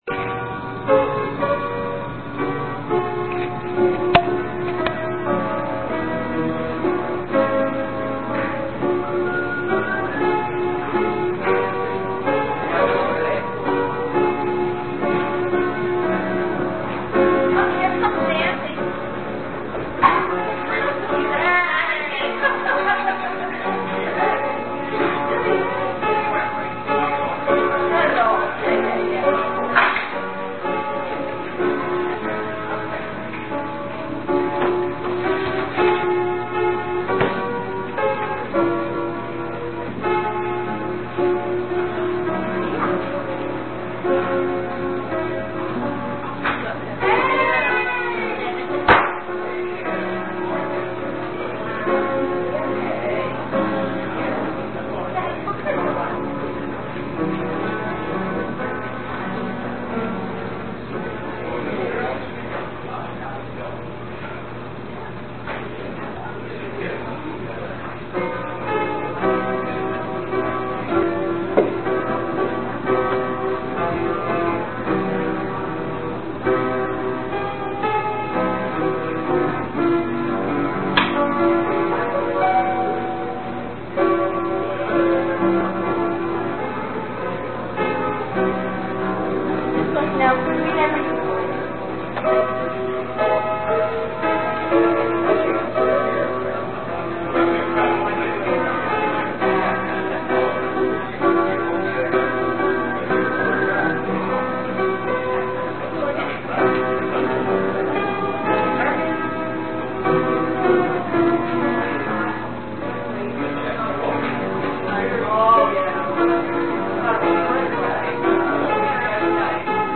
Here are the details for our service on February 8, 2009.